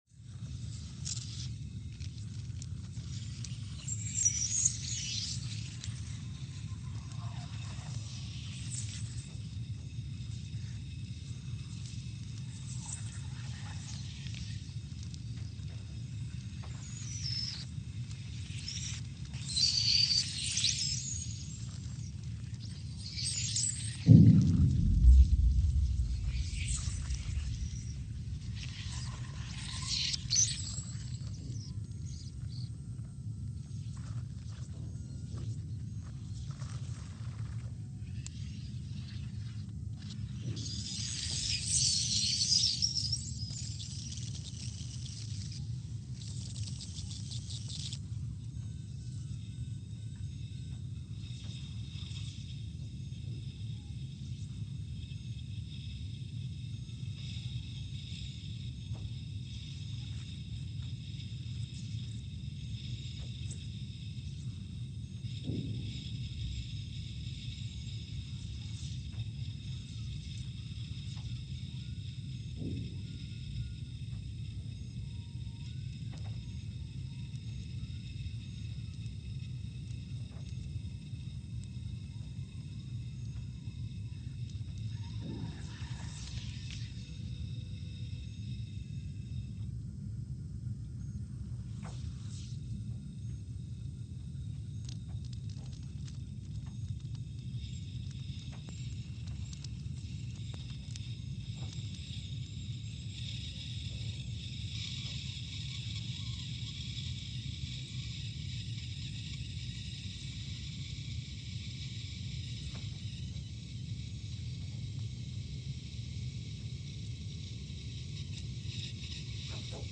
Scott Base, Antarctica (seismic) archived on October 11, 2019
Station : SBA (network: IRIS/USGS) at Scott Base, Antarctica
Speedup : ×500 (transposed up about 9 octaves)
Loop duration (audio) : 05:45 (stereo)